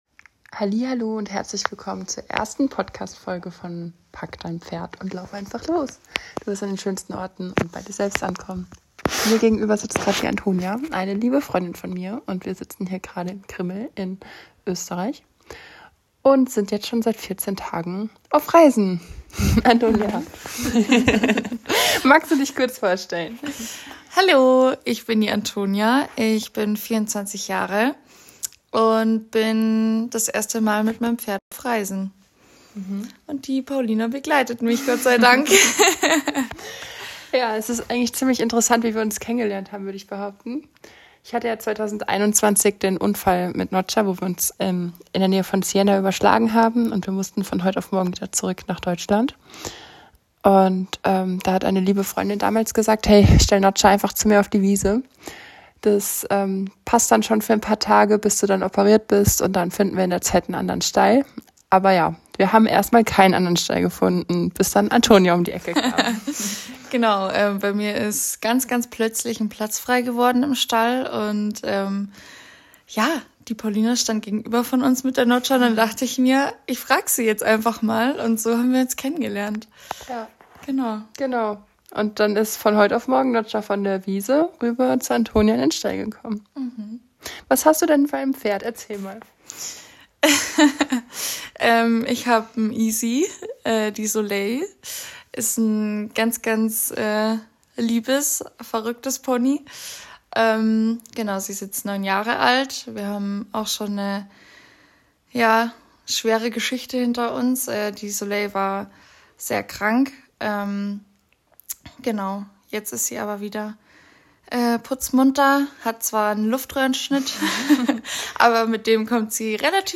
PS: diese Folge haben wir ganz spontan mit dem Handy im Bett aufgenommen. Bitte verzeiht uns die nicht perfekte Tonqualität, wir wollten Euch die Erlebnisse unserer Reise nicht vorenthalten.